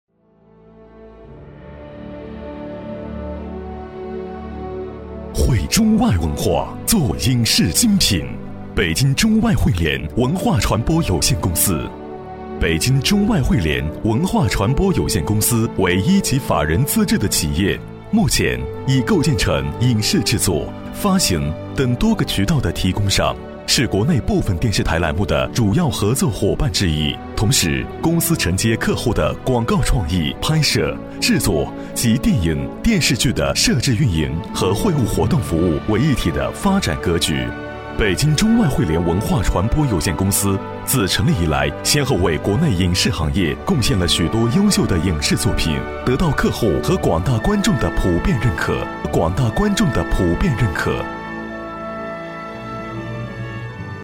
• 男S317 国语 男声 宣传片-北京中外汇联-文化公司宣传片-成熟稳重 沉稳|娓娓道来|积极向上